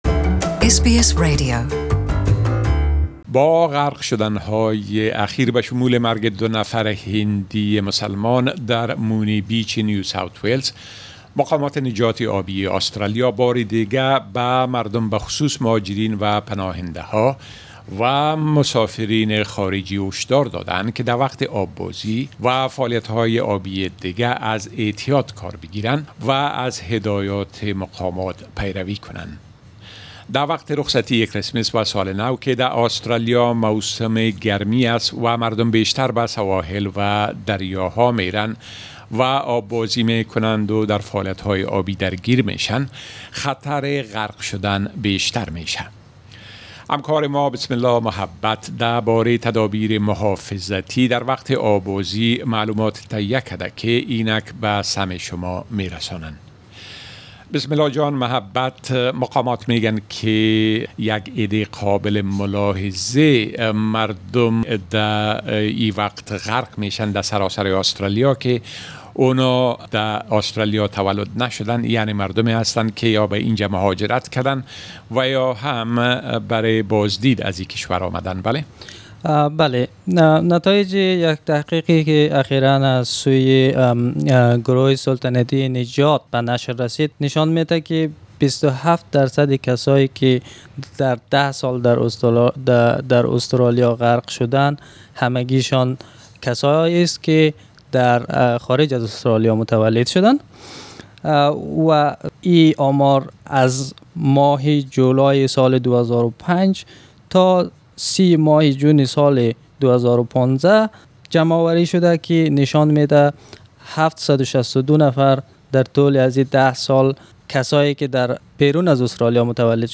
In view of the recent drownings particularly the ones in Moonee Beach, Authorities plead with people to take precautions while swimming or getting involved in other forms of water activities this summer in the Australian beaches and waterways. A discussion about this can be heard here in Dari language.